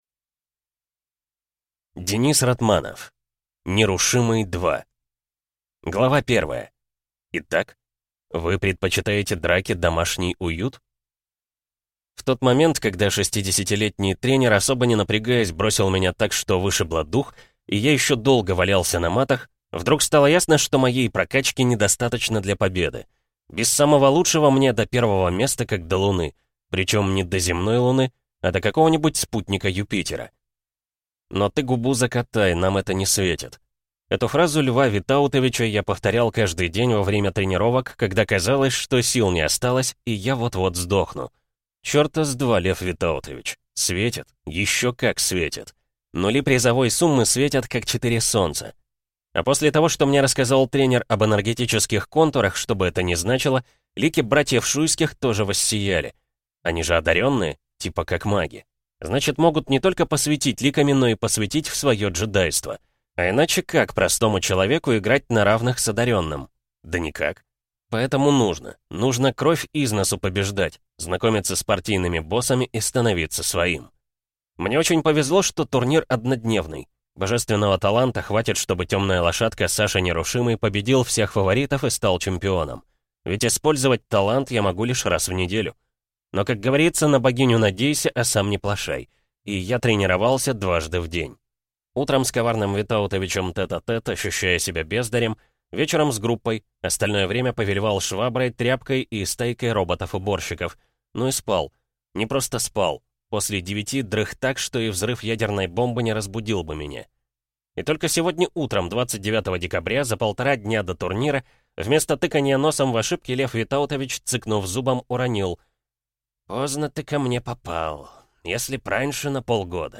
Аудиокнига Нерушимый – 2 | Библиотека аудиокниг